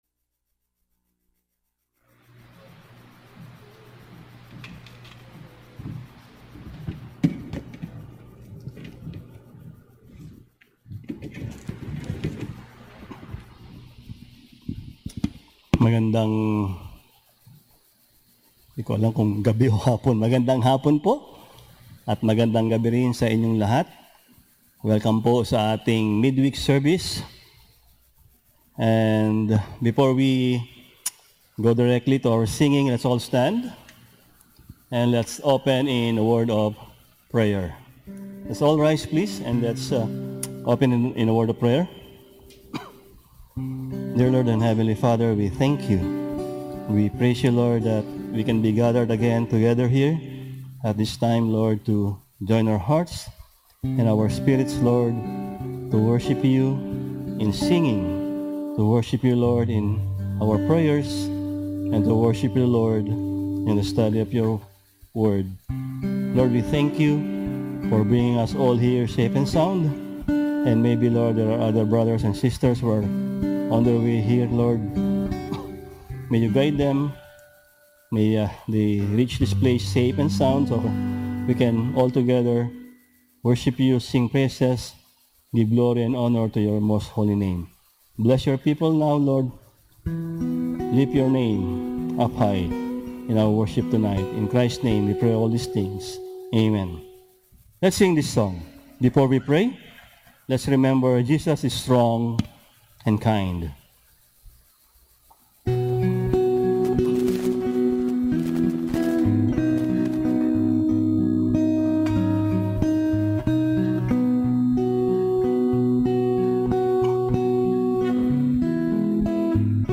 Service: Midweek Sermon